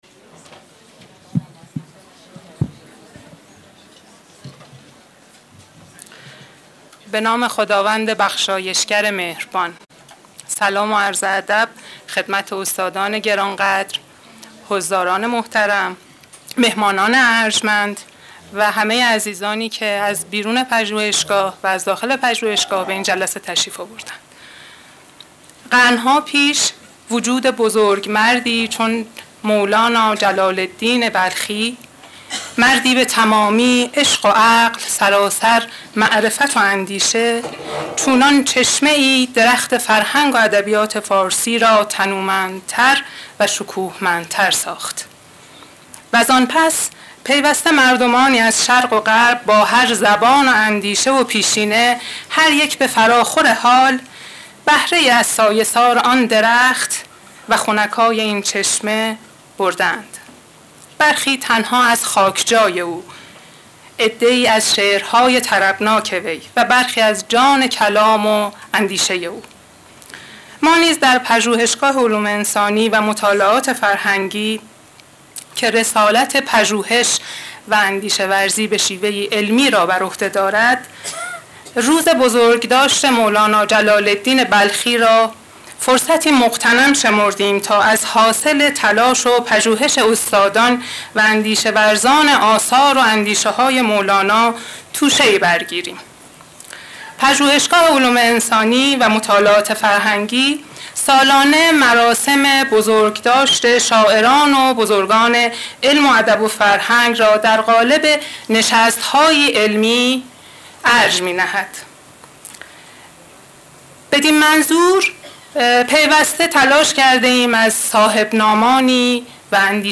بزرگداشت روز مولانا سخنران: استاد کریم زمانی
سالن: حکمت